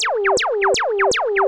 Zapps_01.wav